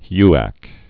(hyăk)